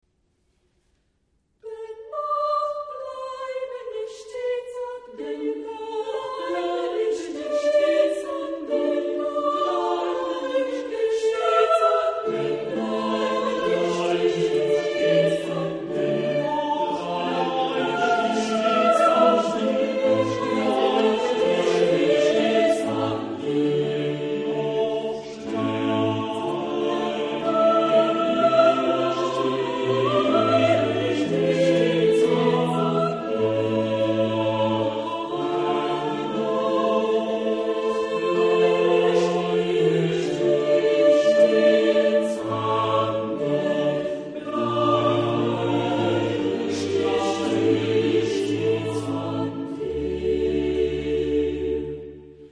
Genre-Stil-Form: geistlich ; Barock ; Motette
Chorgattung: SSATB  (5 gemischter Chor Stimmen )
Instrumentation: Continuo  (1 Instrumentalstimme(n))
Instrumente: Cembalo (1) oder Orgel (1)
Tonart(en): d-moll